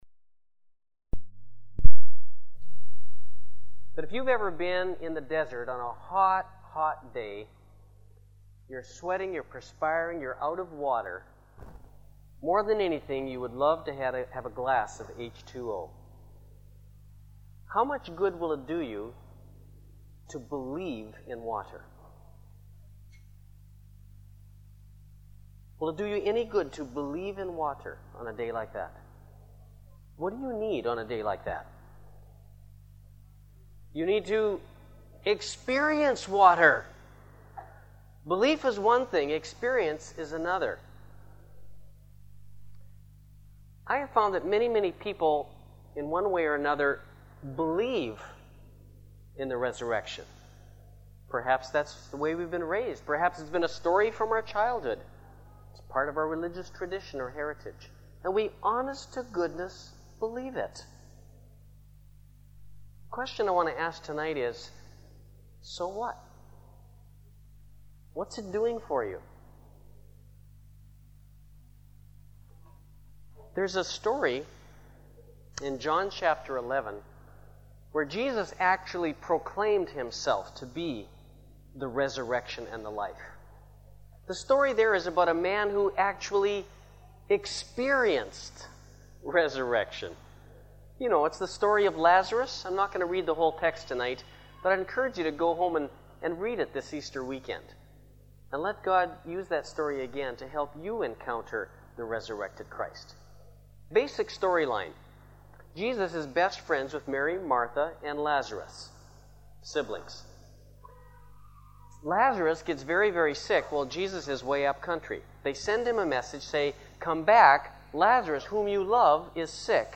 Easter Resurrection Service